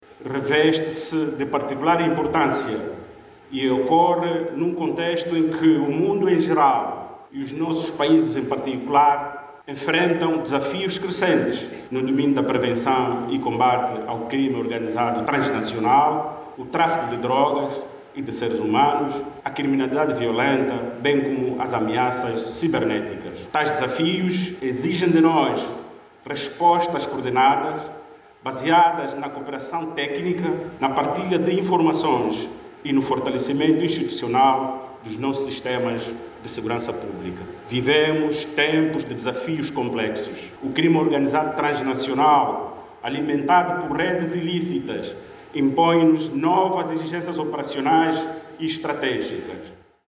Na abertura dos trabalhos de peritos, o Secretário de Estado do Interior, Arnaldo Manuel Carlos, sublinhou os desafios do mundo no domínio da segurança interna, destacando a necessidade de respostas operacionais coordenadas.